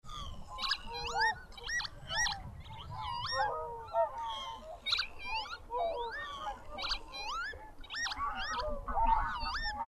Sonogram of Lapwing in aerial display
Hornborgasjön, Västergötland, Sweden  58° 16' 23.9" N  13° 29' 52.6" E  2 April 2010, 2110h
Territorial song from displaying bird after dusk (Whooper Swans in the background).